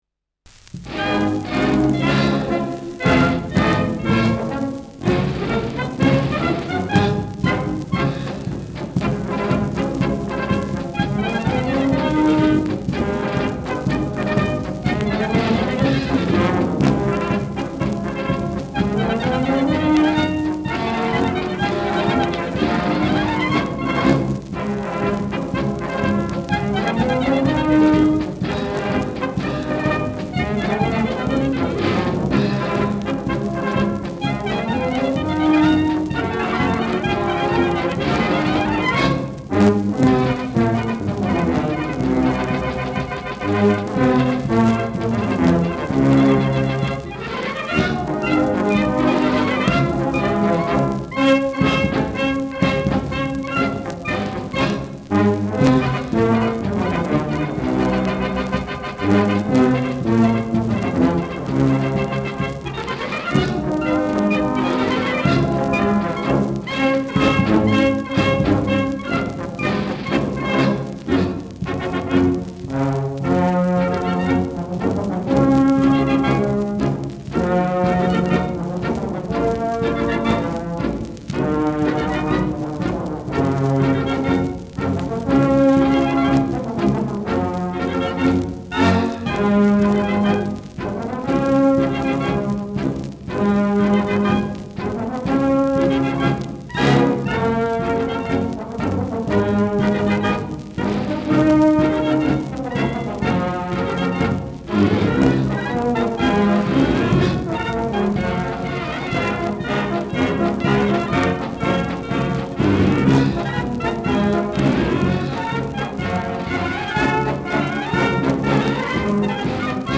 Schellackplatte
leichtes Knistern : leichtes Rauschen
[Berlin] (Aufnahmeort)